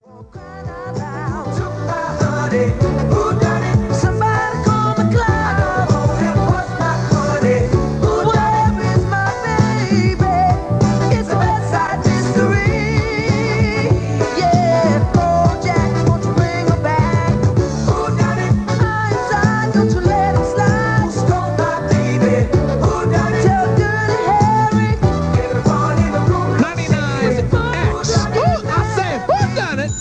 TEST Airchecks WXLO 4/14/77
Airchecks-WXLO-4-14-77.mp3